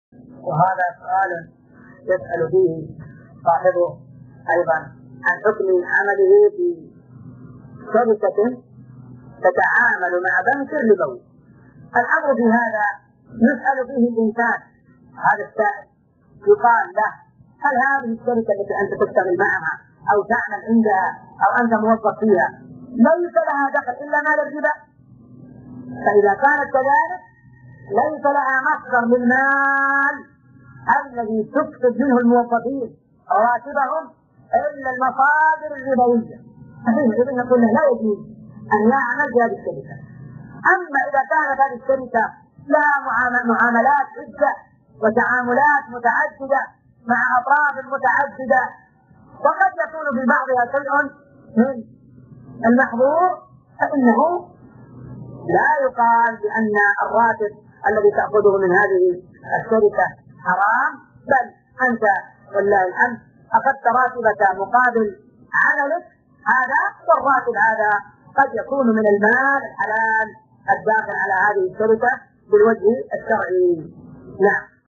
مقتطف من شرح كتاب الصيام من زاد المستقنع .
ملف الفتوي الصوتي عدد الملفات المرفوعه : 1